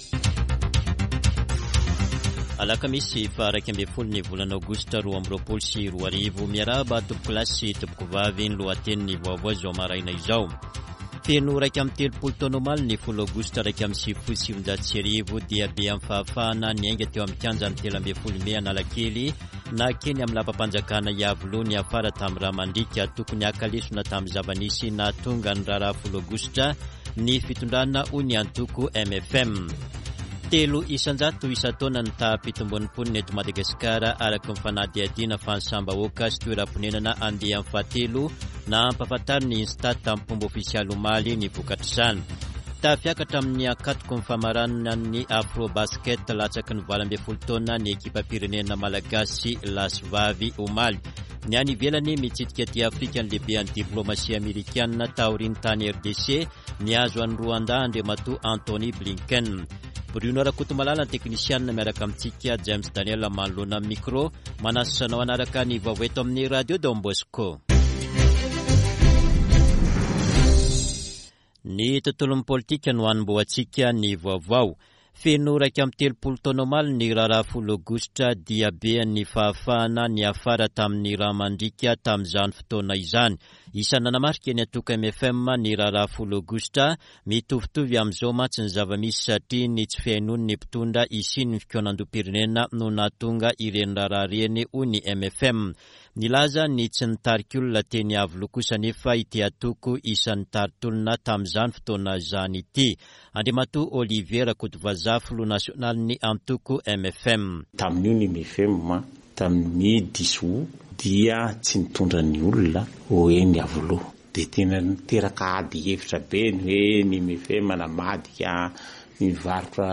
[Vaovao maraina] Alakamisy 11 aogositra 2022